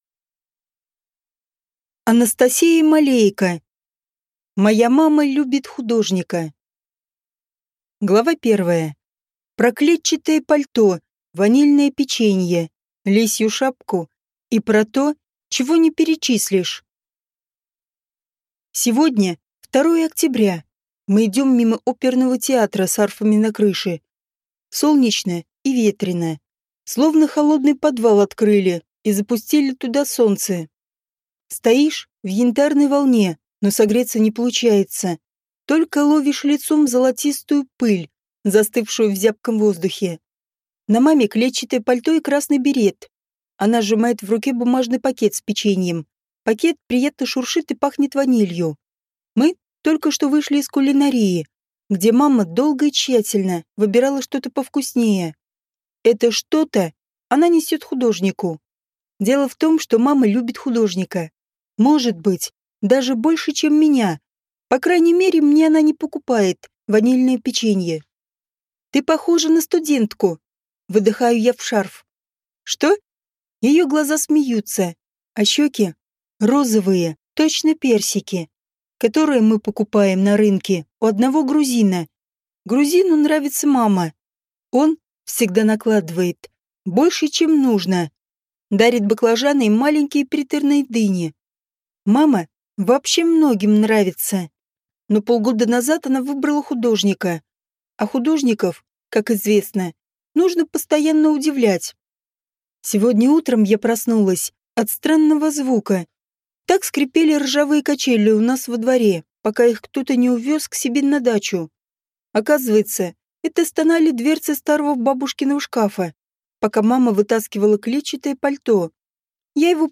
Аудиокнига Моя мама любит художника | Библиотека аудиокниг